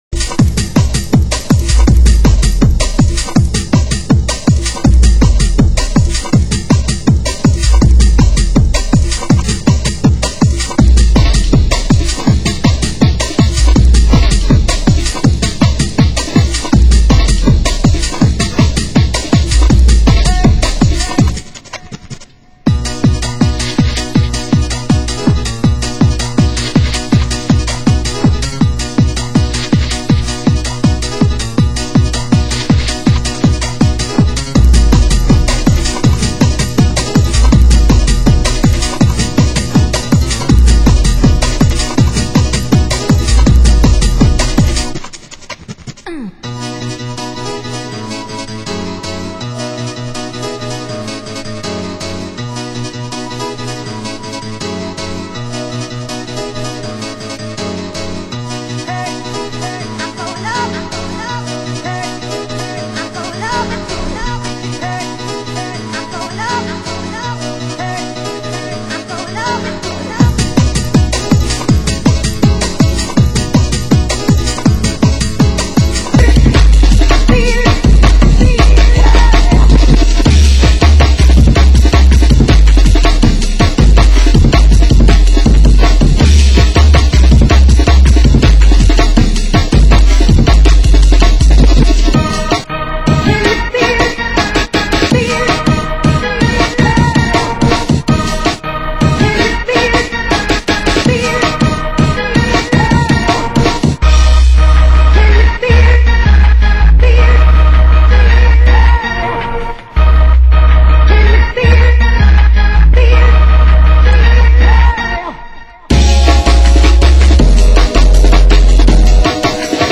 Genre: Happy Hardcore